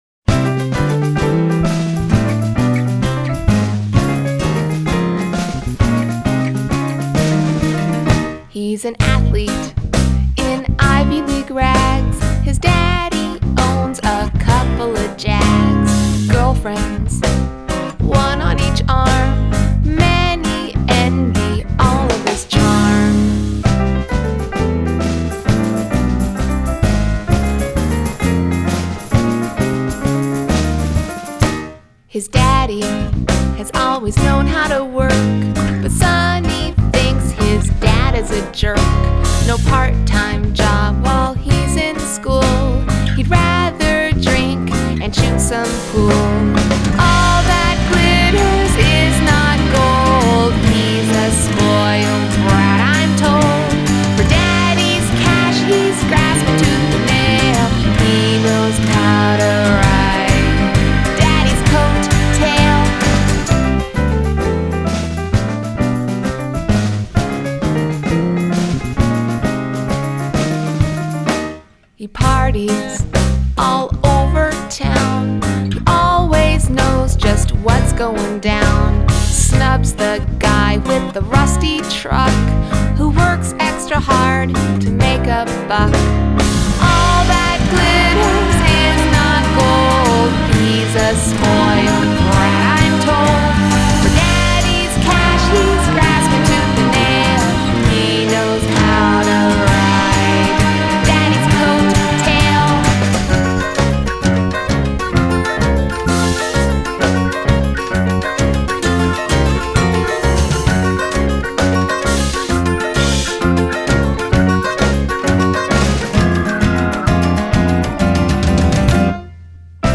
Adult Contemporary , Comedy , Indie Pop
Musical Theatre